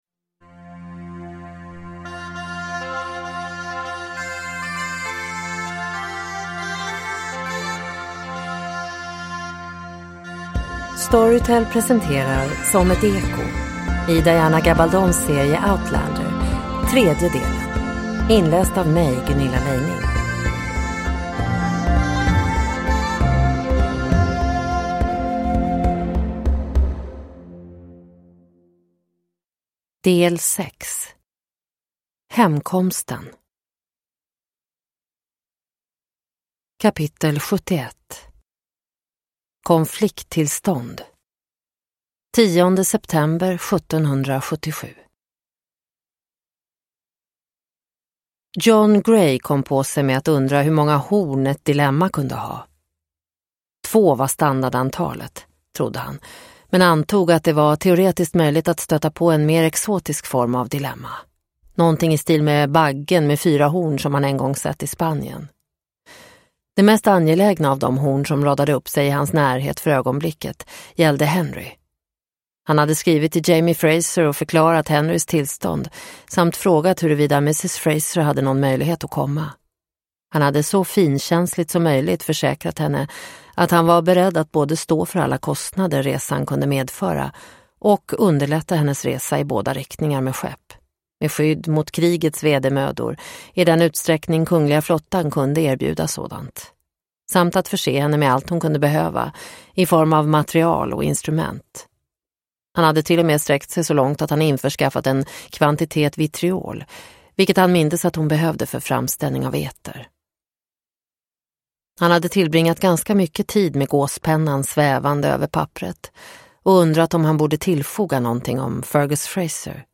Som ett eko - del 3 – Ljudbok – Laddas ner